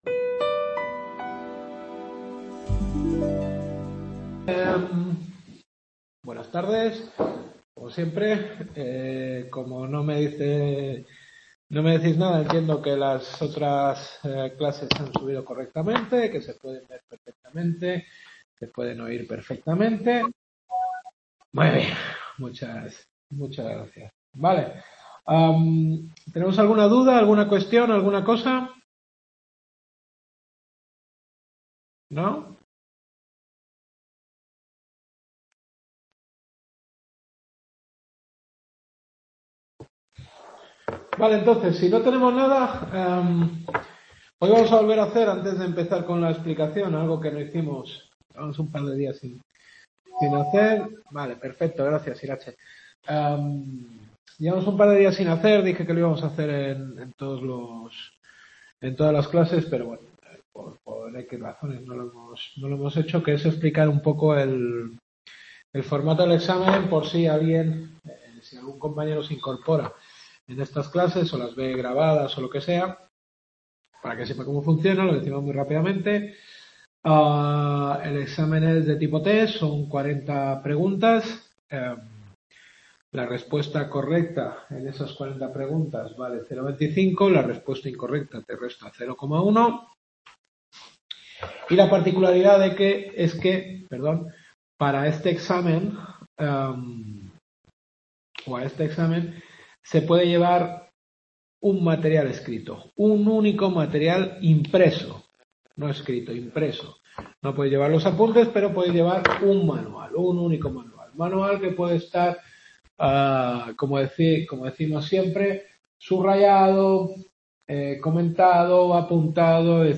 Derecho Administrativo Europeo. Sexta Clase.